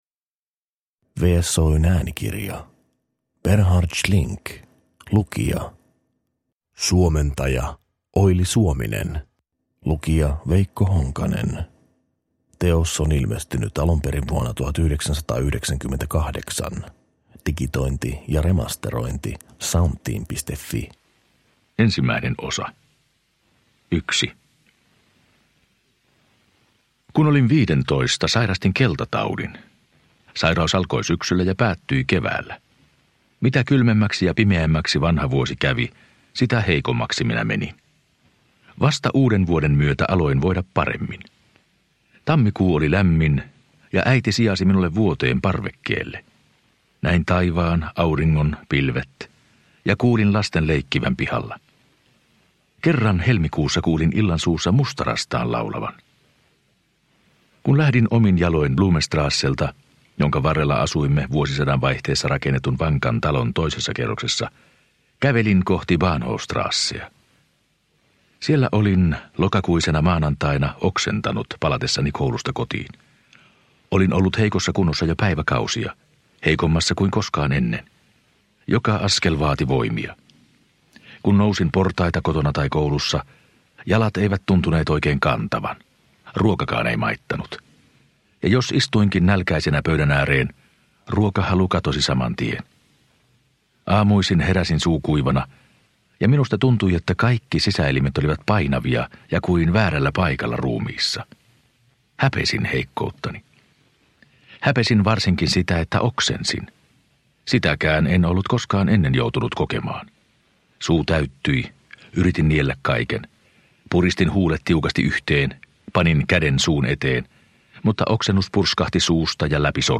Lukija – Ljudbok – Laddas ner